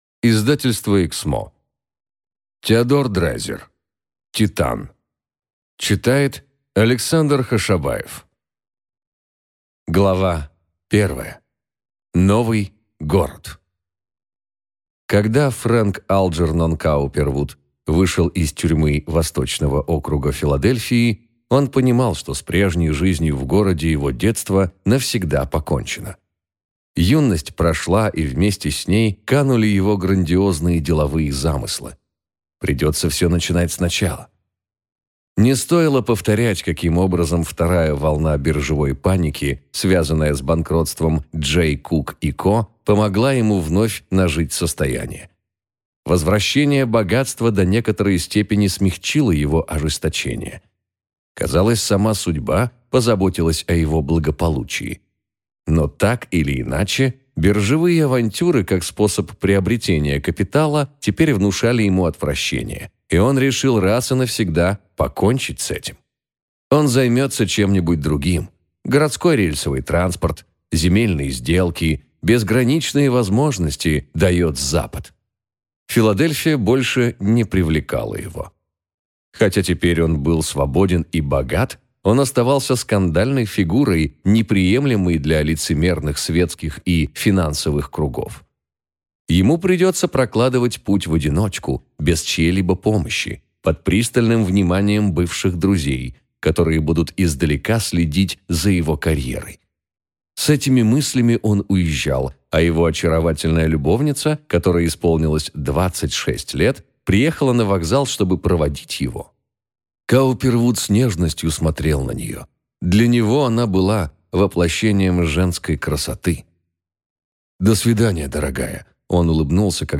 Аудиокнига Титан | Библиотека аудиокниг
Прослушать и бесплатно скачать фрагмент аудиокниги